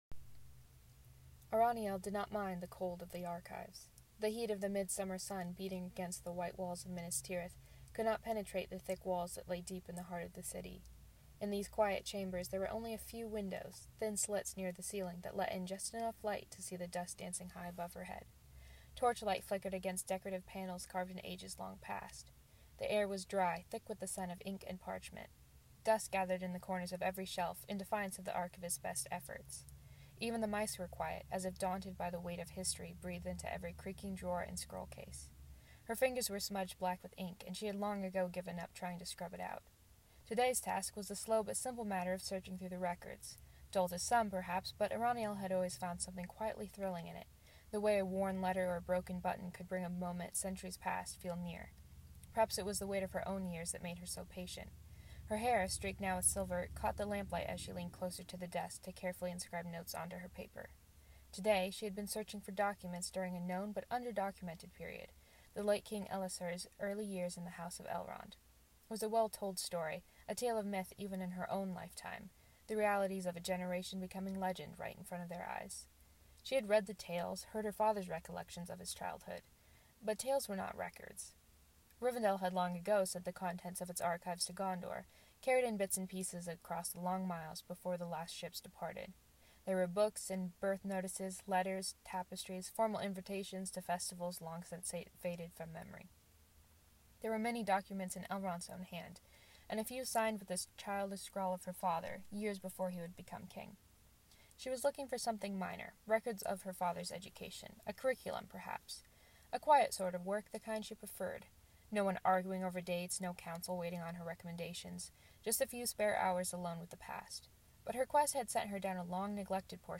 This story was created for Mereth Aderthad 2025, to accompany the presentation "'Kidnap Fam' and the Living Legendarium."